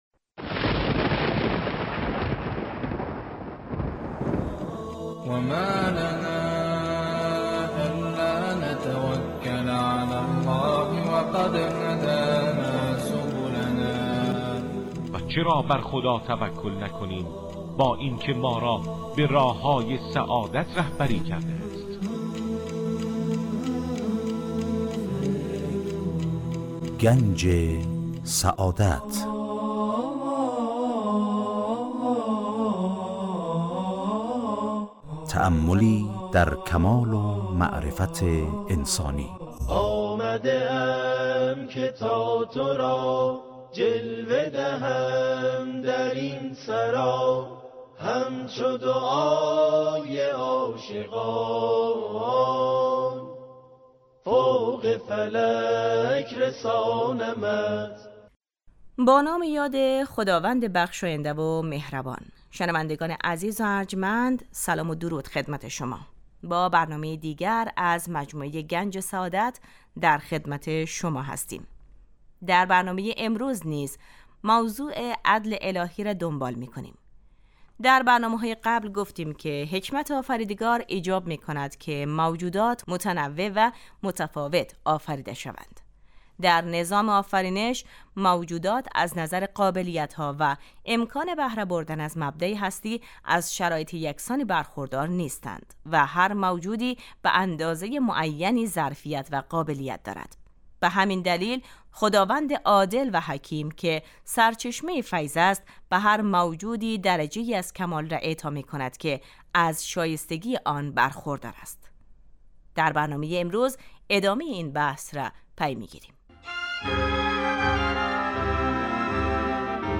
در این برنامه سعی می کنیم موضوعاتی همچون ؛ آفرینش ، یکتاپرستی و آثار و فواید آن، همچنین فلسفه و اهداف ظهور پیامبران را از منظر اسلام مورد بررسی قرار می دهیم. موضوعاتی نظیر عدل خداوند، معاد و امامت از دیگر مباحثی است که در این مجموعه به آنها پرداخته می شود این برنامه هر روز به جزء جمعه ها حوالی ساعت 12:35 از رادیودری پخش می شود.